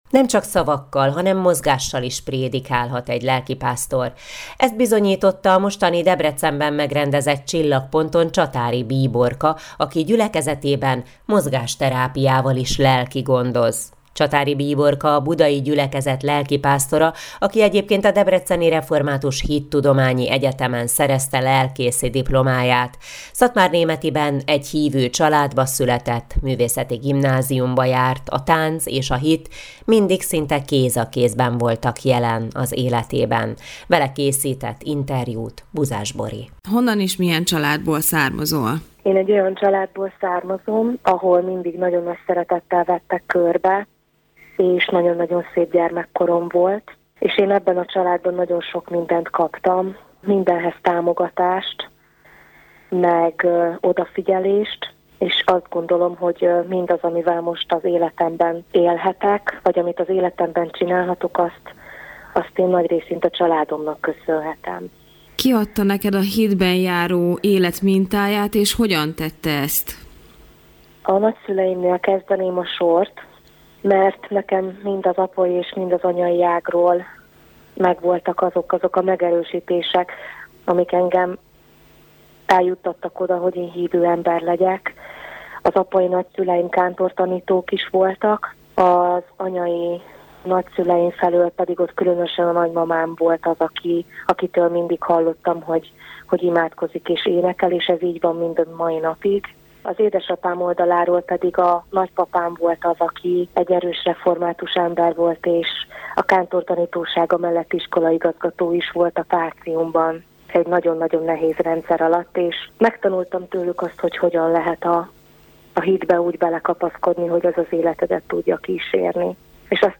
dedeeltancolt_predikacio.mp3